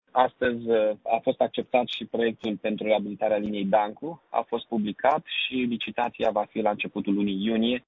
Mihai Chirica a anunţat şi alte măsuri complementare, cum ar fi modernizarea trasportului în comun, inclusiv prin reabilitarea unor linii de tramvai cum este cea din Dancu: